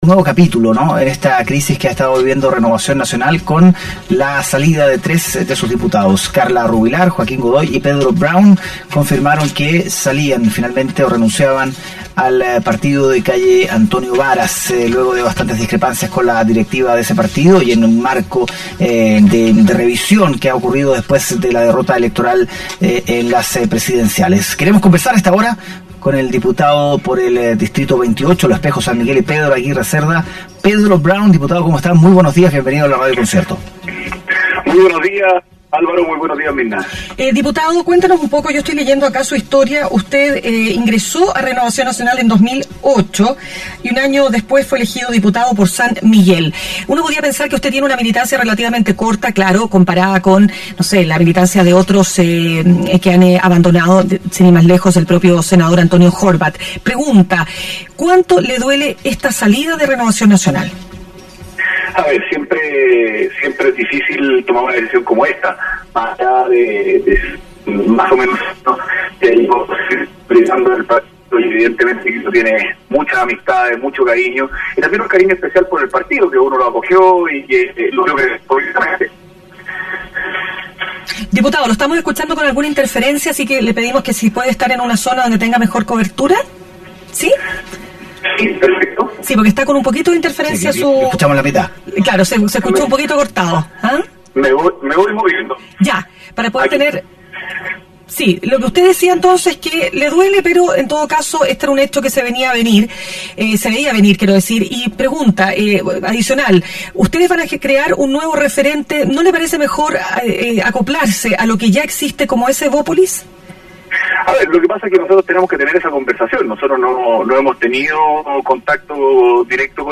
Este miércoles en Mañana Será Otro Día, conversamos con el diputado Pedro Browne, sobre la oficialización de su renuncia a Renovación Nacional.